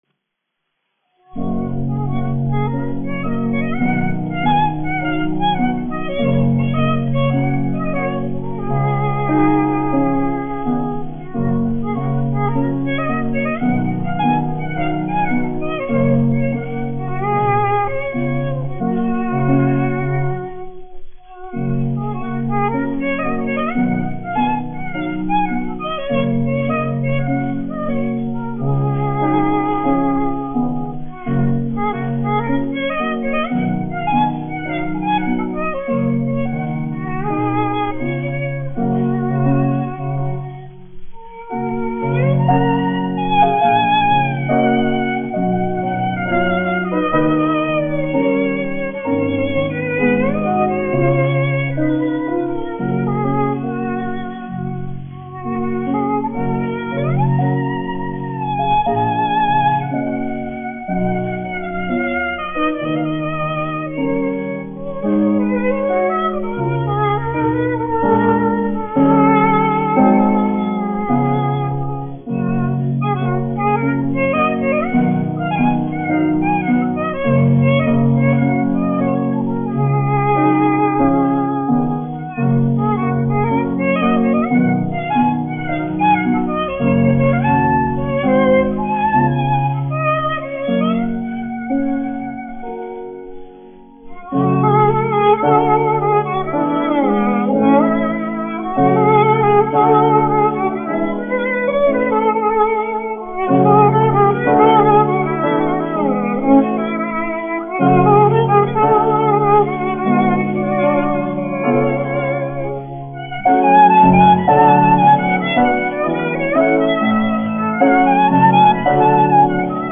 1 skpl. : analogs, 78 apgr/min, mono ; 25 cm
Vijoles un klavieru mūzika, aranžējumi
Latvijas vēsturiskie šellaka skaņuplašu ieraksti (Kolekcija)